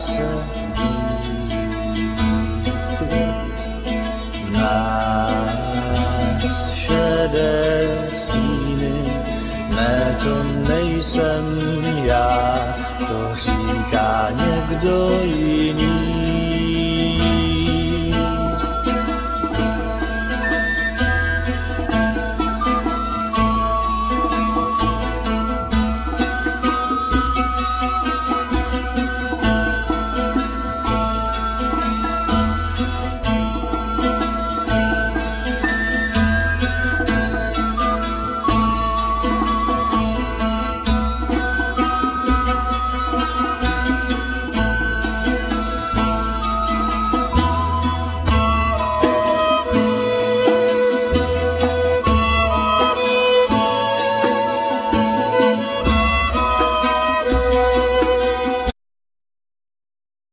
Flute,Voice
Violin,Viola,Voice
Double bass
Darbuka,Djembe
Sitar,Voice